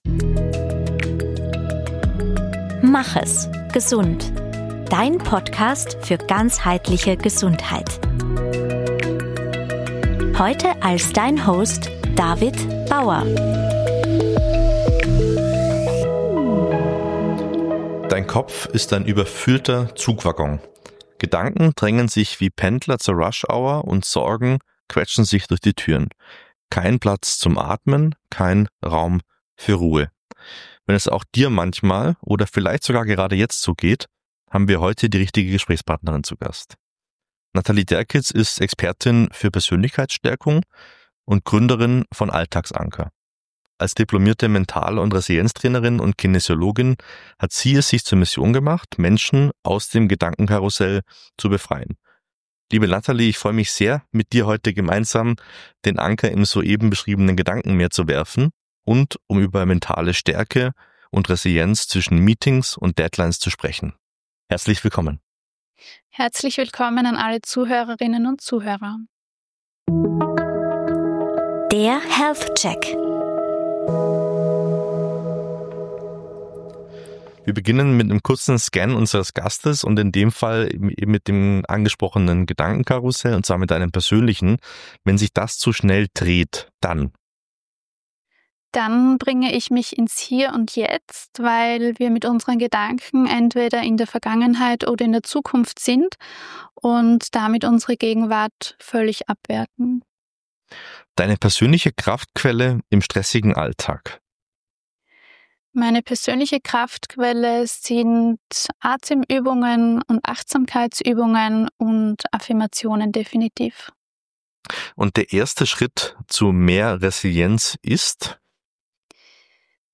- und: machen eine geführte Atemübung – live im Podcast zum Mitmachen MACH ES! gesund